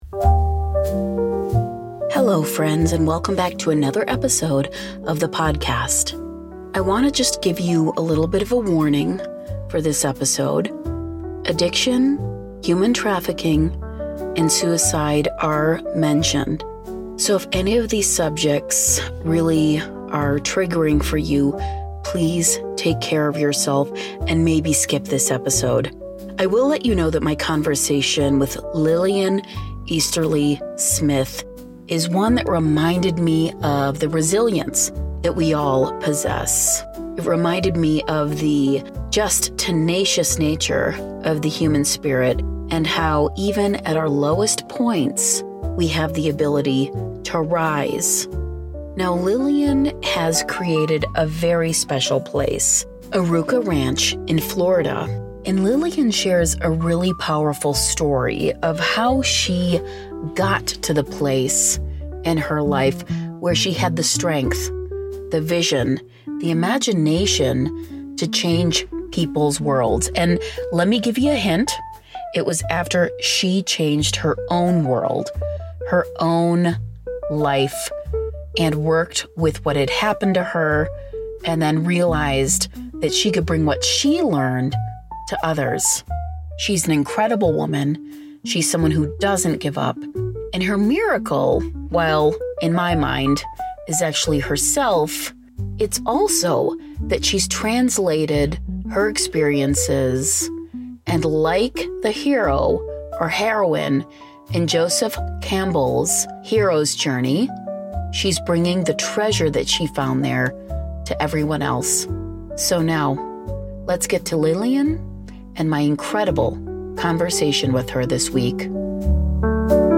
Byte Sized Blessings / Interview